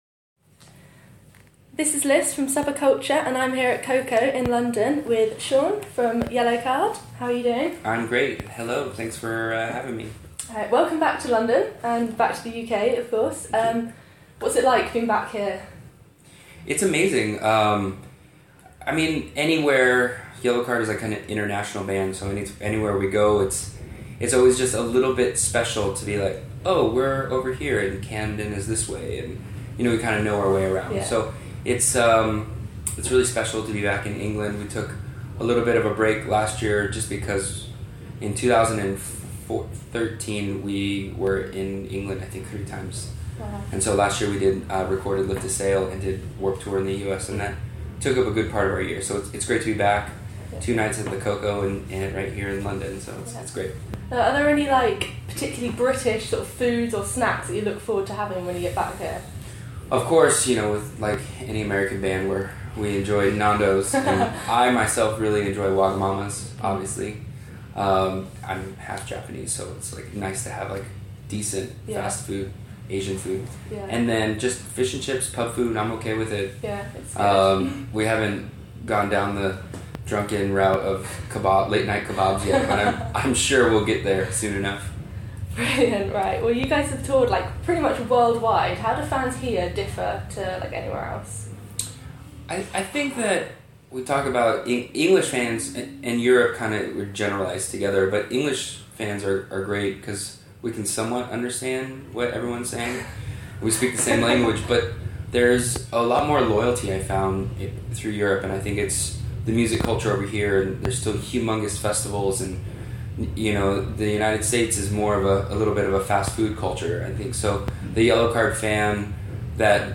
Subba-Cultcha chats with Yellowcard's Sean Mackin